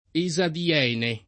[ e @ adi- $ ne ]